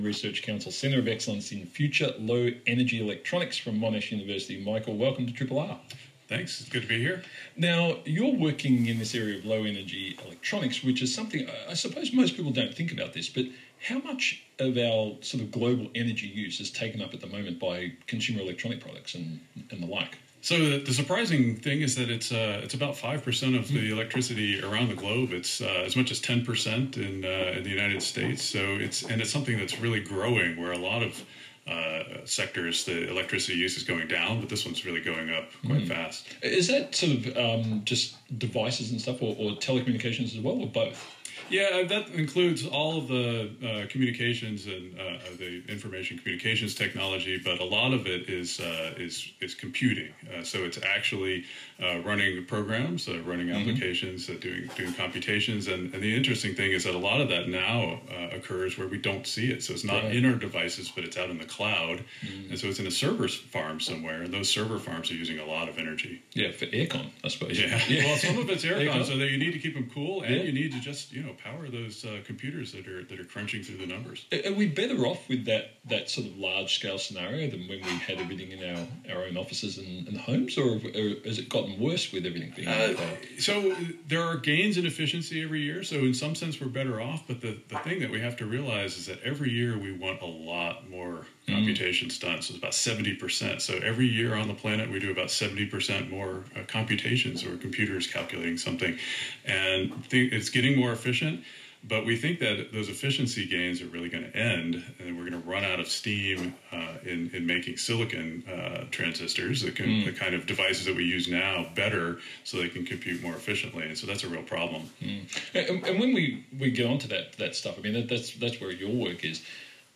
Wolfgang Ketterle introduces Red to new states: ABC Radio Melbourne
Prof Wolfgang Ketterle, in Australia with FLEET, teaches ABC Radio Melbourne’s Red Symons a thing or two about states of matter. In particular, Bose Einstein condensates.